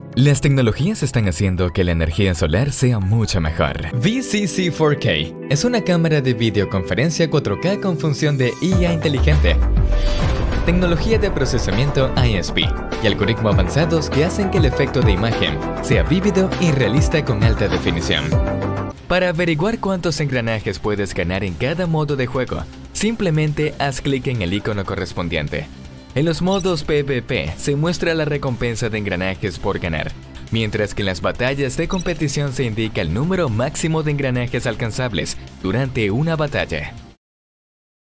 Espagnol (Amérique Latine)
Commerciale, Jeune, Naturelle, Enjouée, Corporative
E-learning